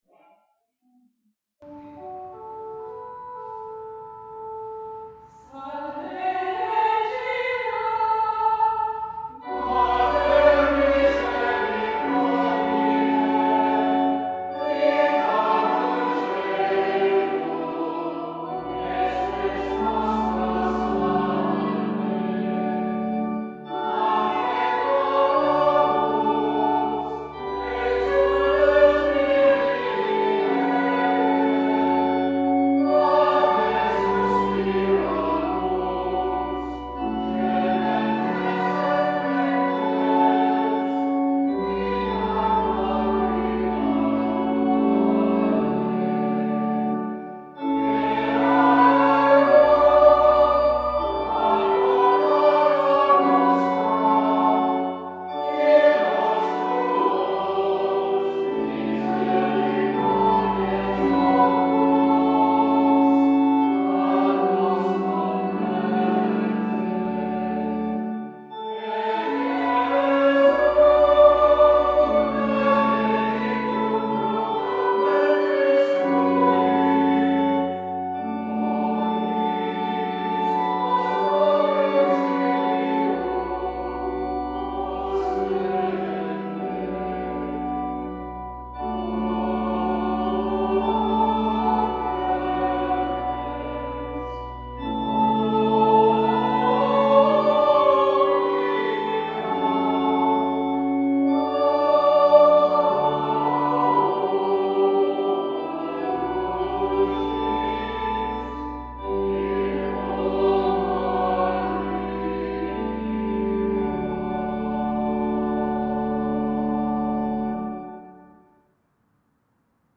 Gregorian Chant: Salve Regina (Simple tone) - Let's Pray with the Mary Immaculate Parish Choir 2:07
The Mary Immaculate Parish choir sings the Salve Regina, also known as the Hail Holy Queen, a cherished Marian hymn composed in Latin during the Middle Ages and originally sung as a processional chant.